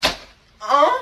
Play, download and share ouch 2 original sound button!!!!
ouch-2.mp3